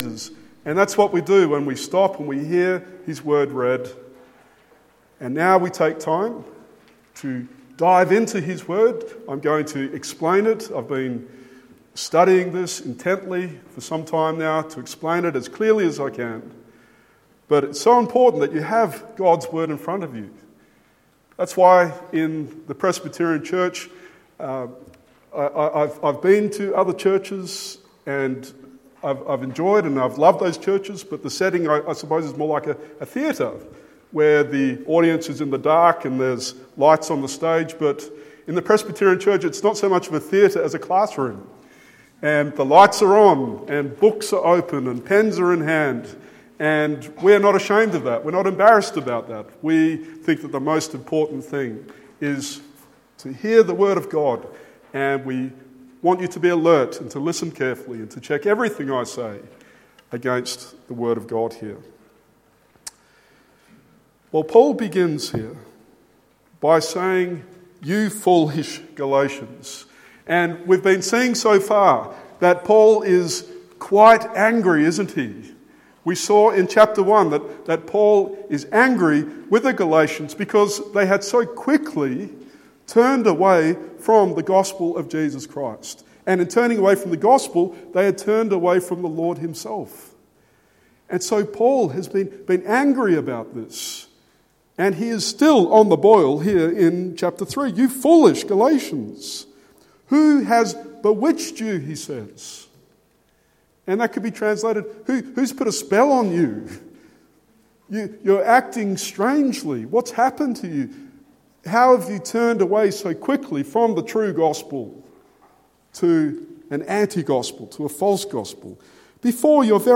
Galatians 3:1-14 Sermon